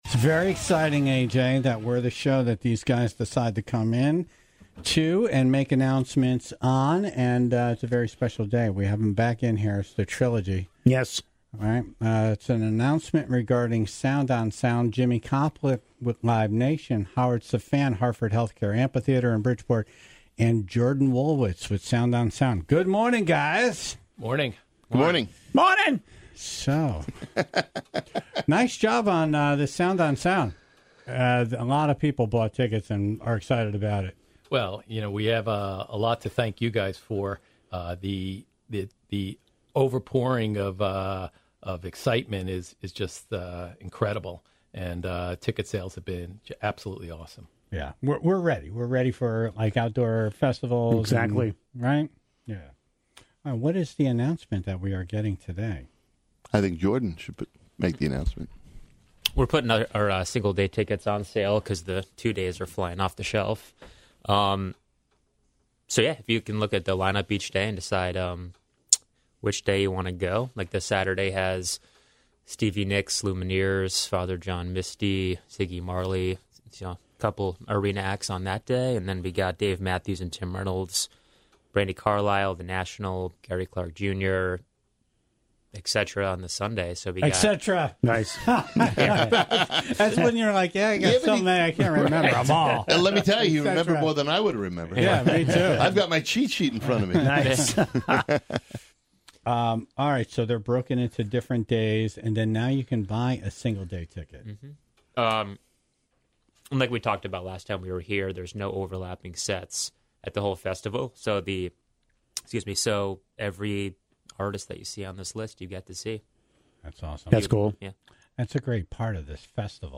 were all in studio together to make some more announcements about the highly anticipated Sound on Sound Music Festival in Bridgeport this September.